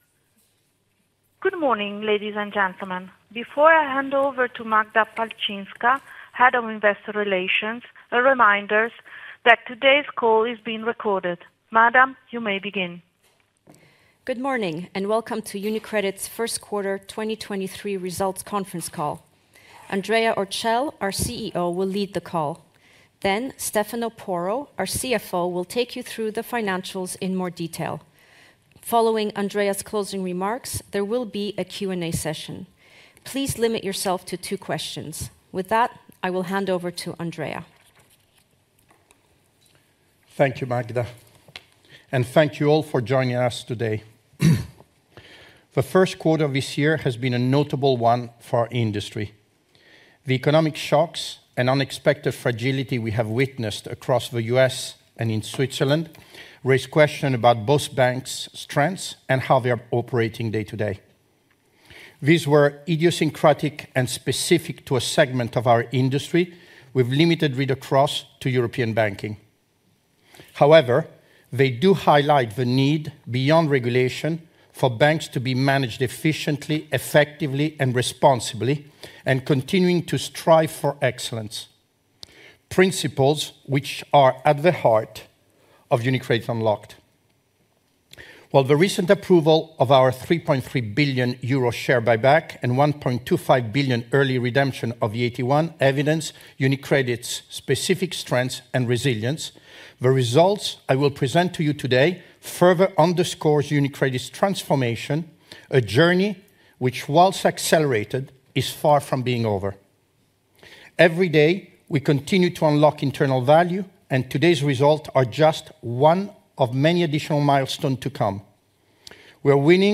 1Q23-UniCredit-Conference-Call.mp3